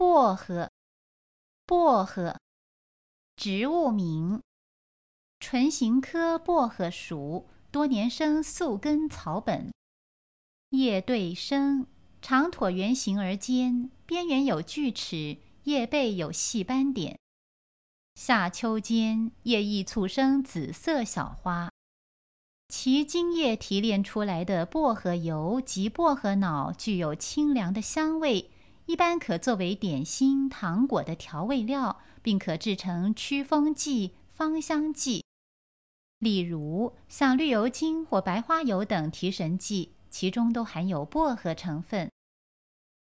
Advanced Information 【薄】 艸 -13-17 Word 薄荷 Pronunciation ㄅㄛ ˋ ㄏㄜ ˊ ▶ Definition 植物名。